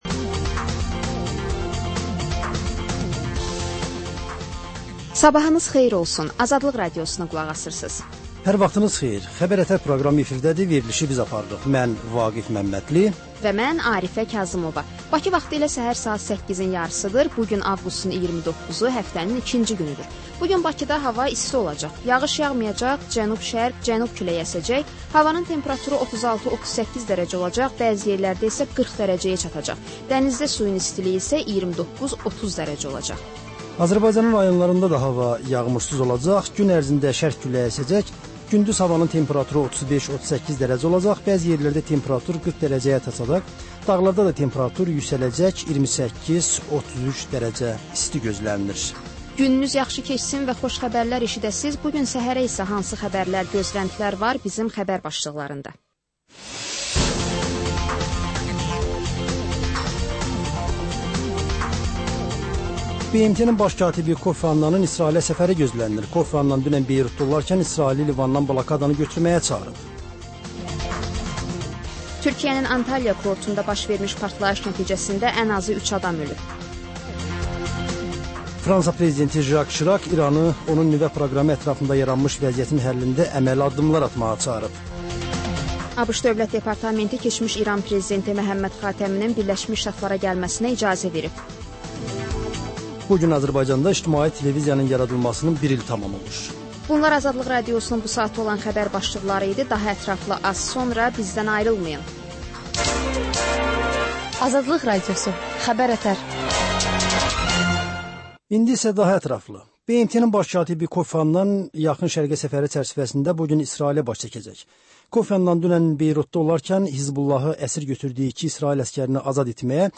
Səhər-səhər, Xəbər-ətərI Xəbər, reportaj, müsahibə